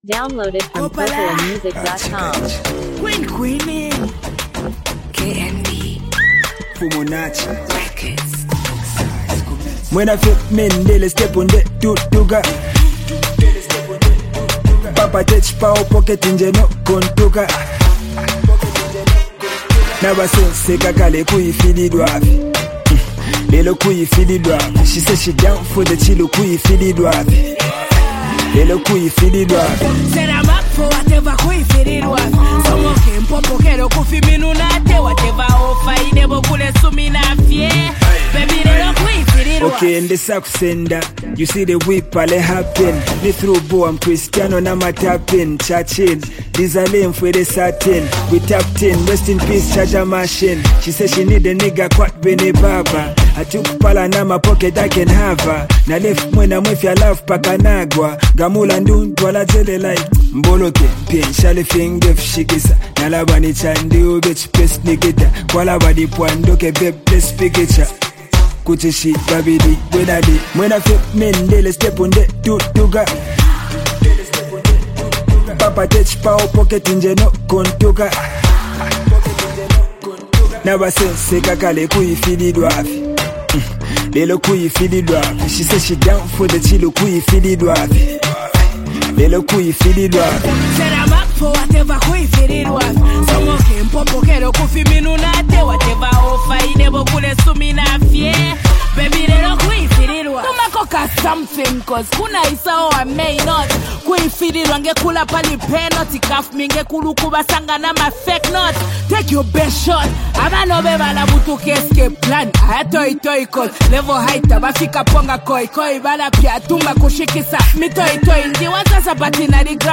MusicZambian Music
hip-hop
bold and confident verses
hard-hitting, infectious beat